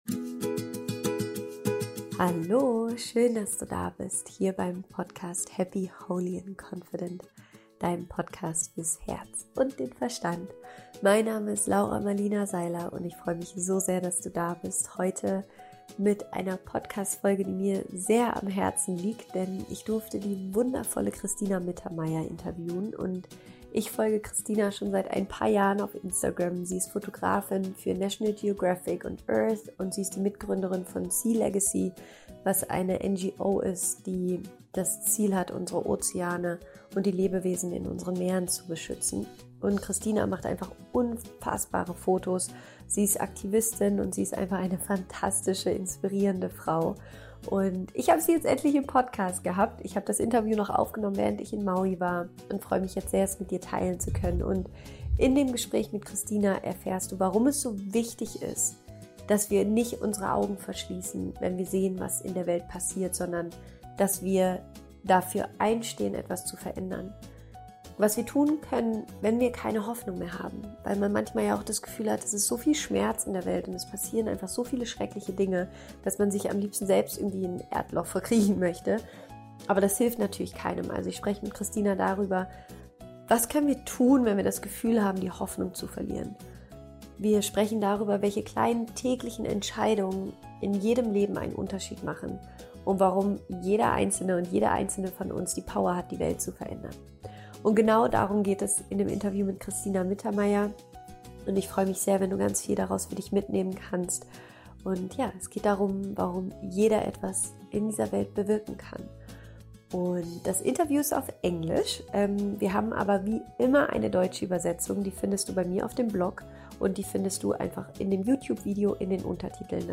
Cristina ist Fotografin für National Geographic und Earth und Mitgründerin der Organisation SeaLegacy.